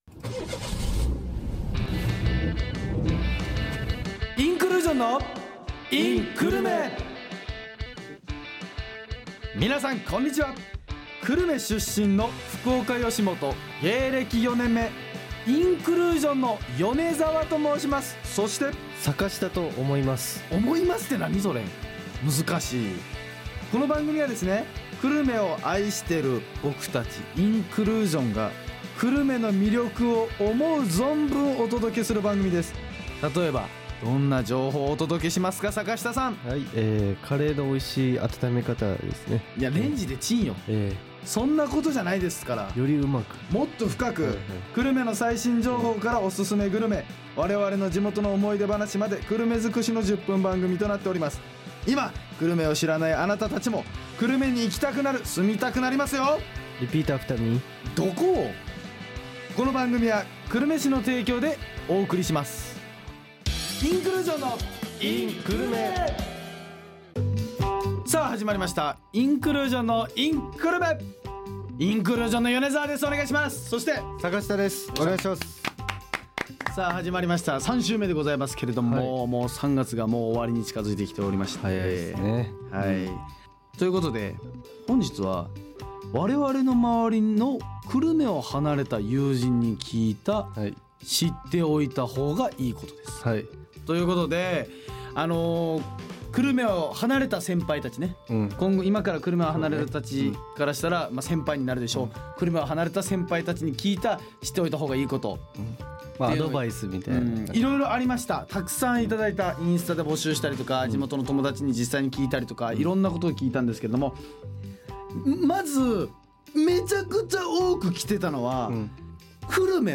桜が咲き始める今の季節に合わせて、インクルージョンのふたりが桜にまつわるエピソードトークを繰り広げます。